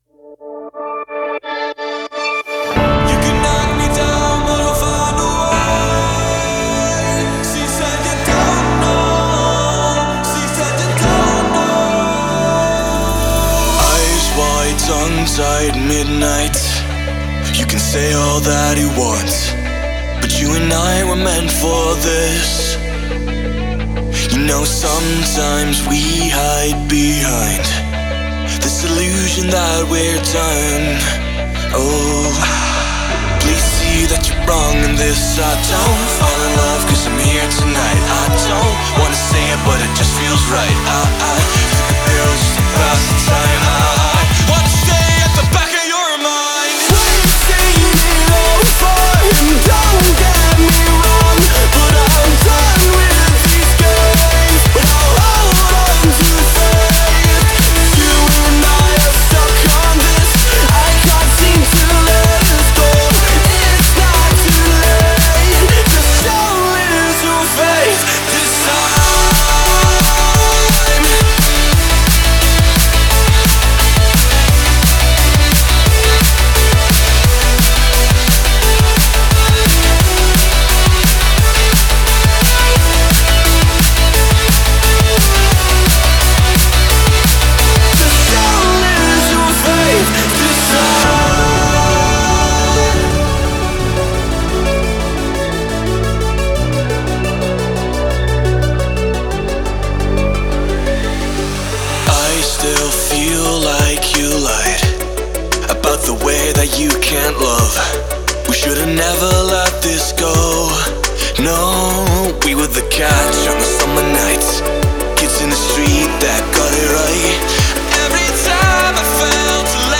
Drum & Bass, Energetic, Epic, Euphoric, Dreamy